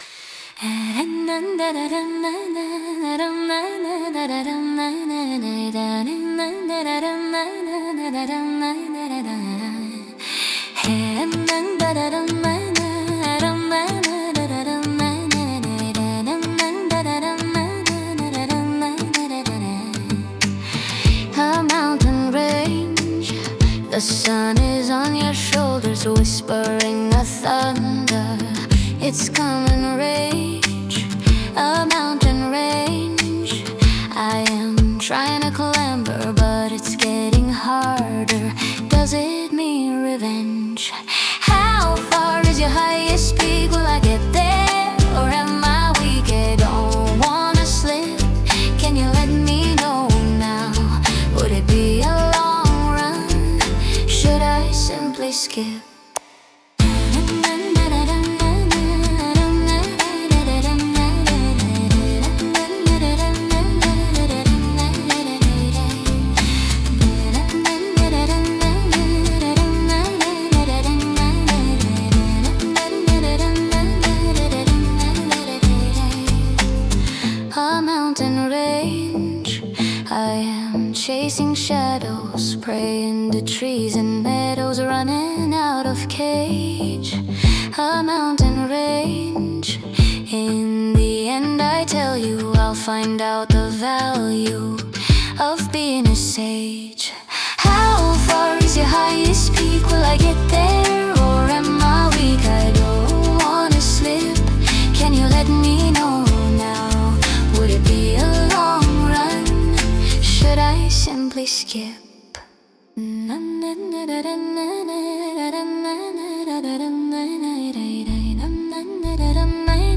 Пример 5: Пружинящий дэнсхолл
дэнсхолл, средний темп, пружинящий ритм, мягкий кик, акценты на слабых долях, живая перкуссия, лёгкий кач